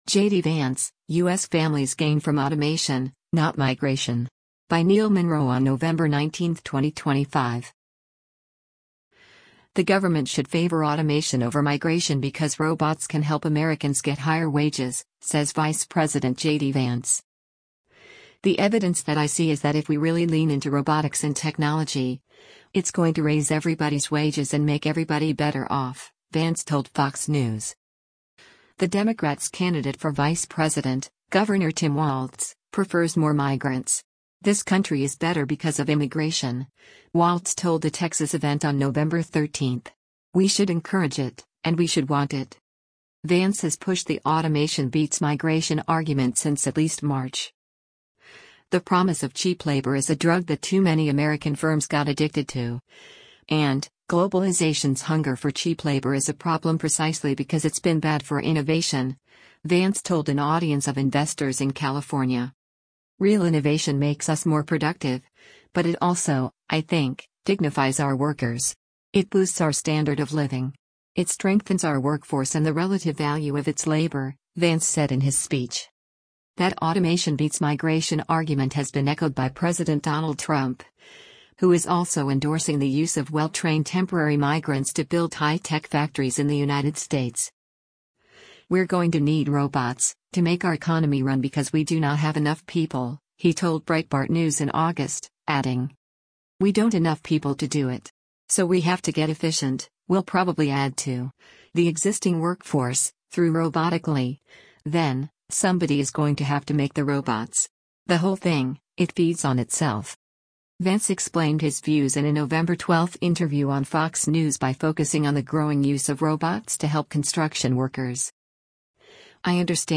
Vance explained his views in a November 12 interview on Fox News by focusing on the growing use of robots to help construction workers.